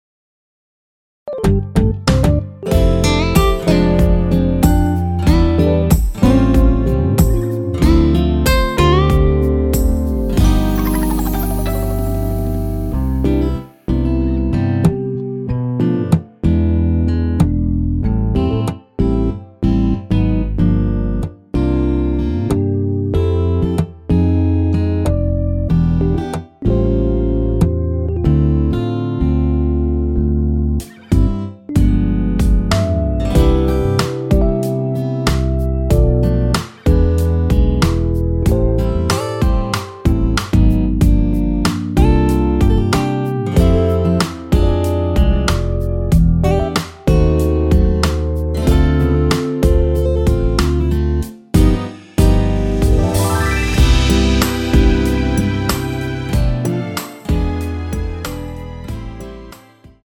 원키에서(-1)내린 MR입니다.
앞부분30초, 뒷부분30초씩 편집해서 올려 드리고 있습니다.
중간에 음이 끈어지고 다시 나오는 이유는